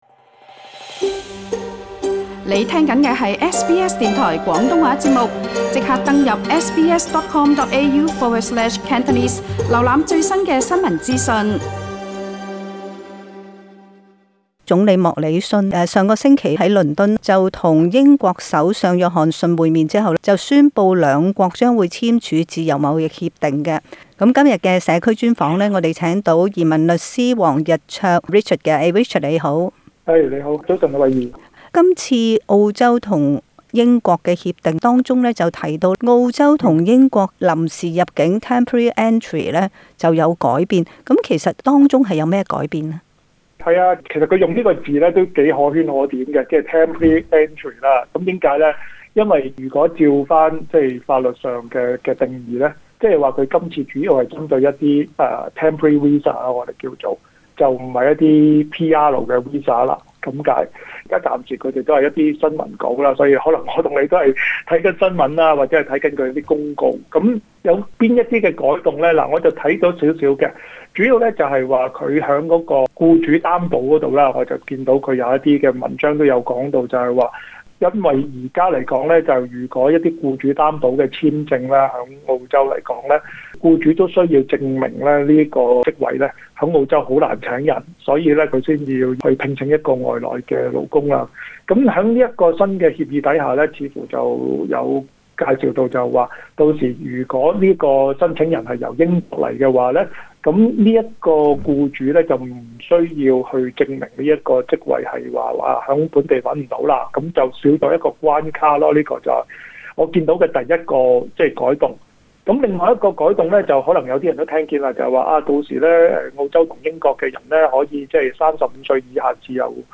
社区专访